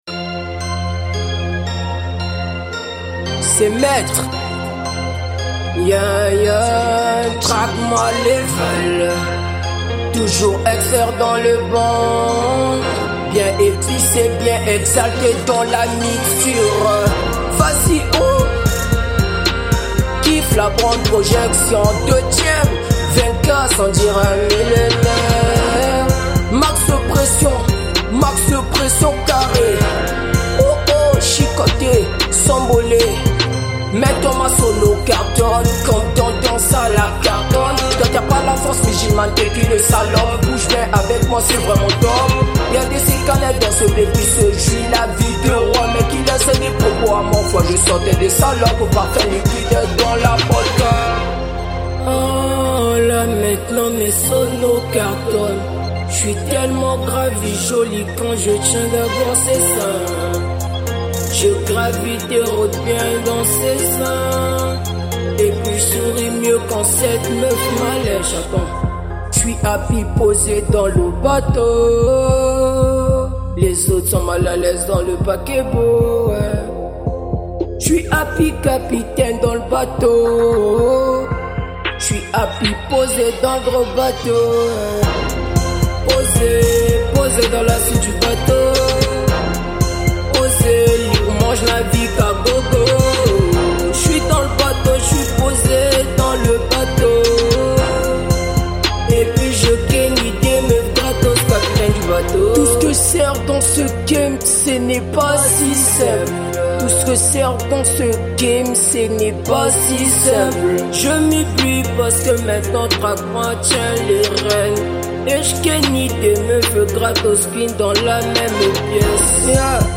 | Afro trap